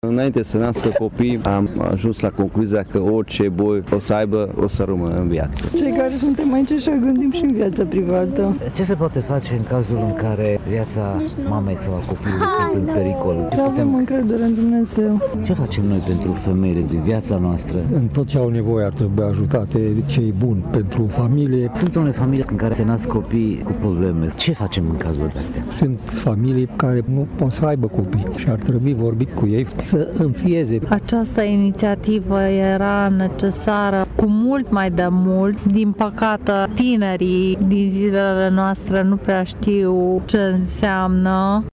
După Sf. Liturghie pentru copiii nenăscuți celebrată la Biserica greco-catolică ”Bunavestire”, în centrul Tg. Mureșului a avut loc, pentru al șaptelea an, marșul pentru viață.
Participanții au fost cu toții de acord că viața trebuie respectată în orice condiții, cu accent pe educarea tinerei generații: